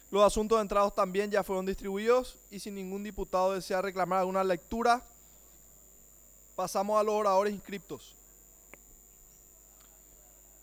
Sesión Ordinaria, 12 de julio de 2023